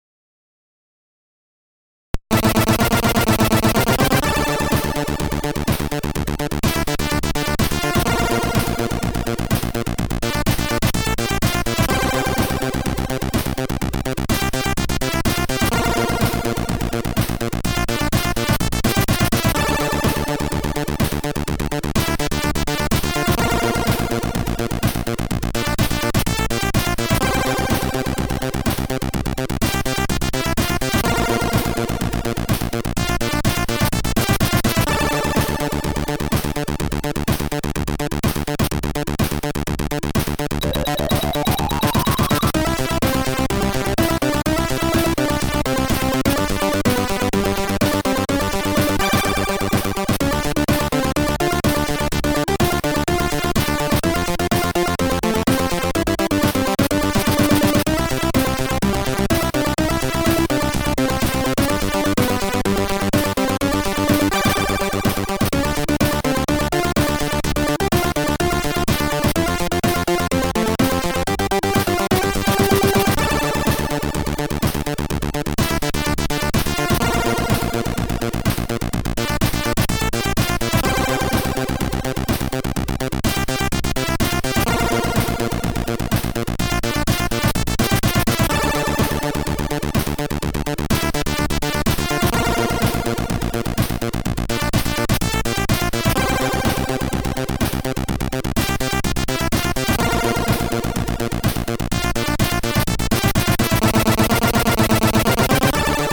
Here's an example of a loader tune for you.
SID Version: 8580 (Commodore 64C)
Comments: Has an 'Ocean Software' sort of feel to it.